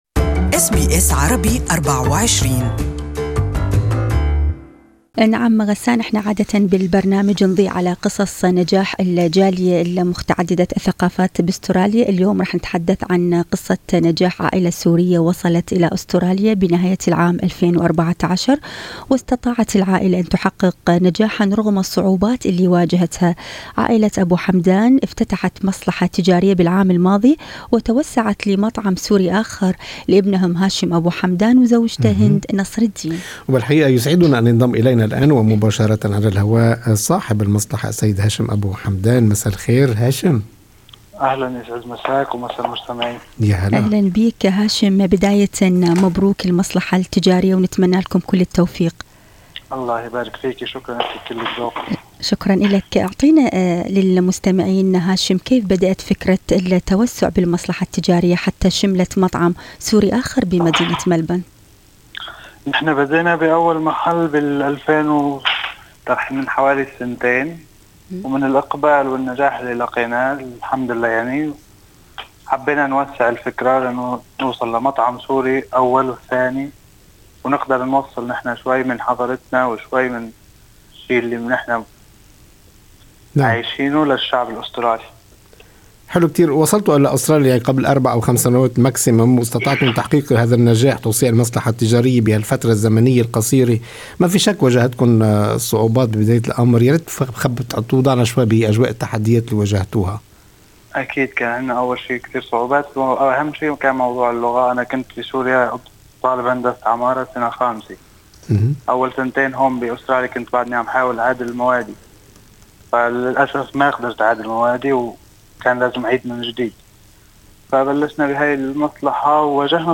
في لقاءِ مع برنامج أستراليا اليوم